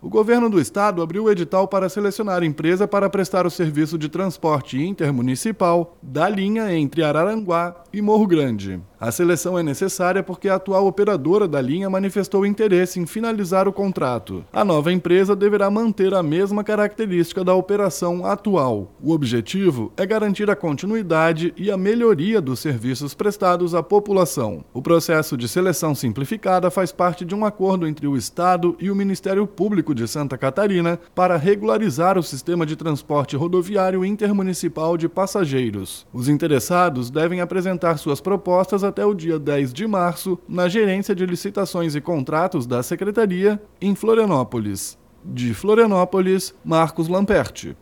BOLETIM – Secretaria de Estado da Infraestrutura e Mobilidade lança Edital de Seleção Simplificada para operação de linha intermunicipal